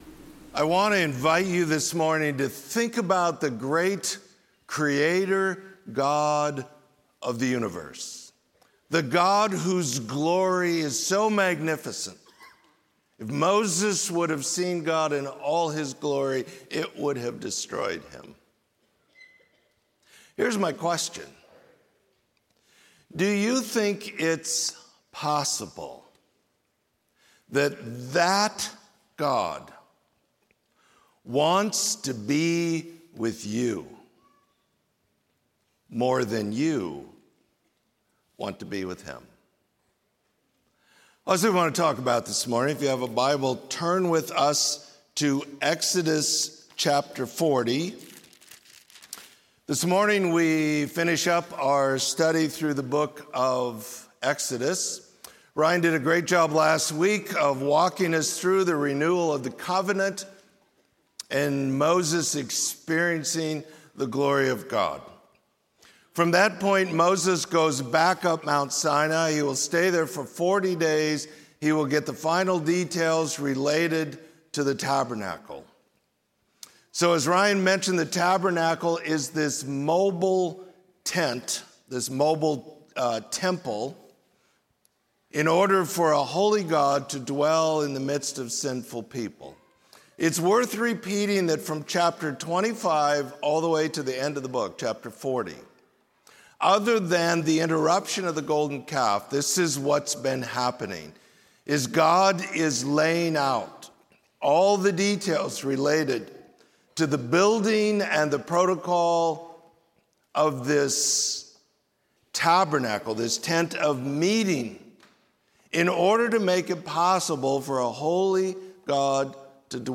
Sermon: The God Who is With Us